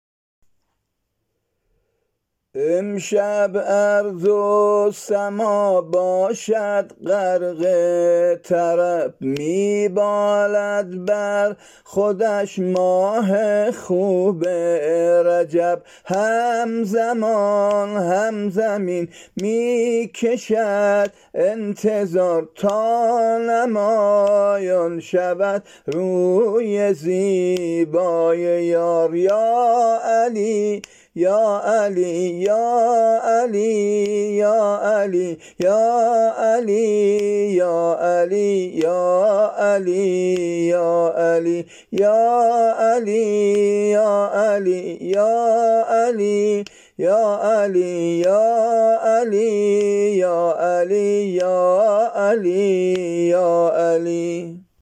سرود میلاد حضرت علی(عج)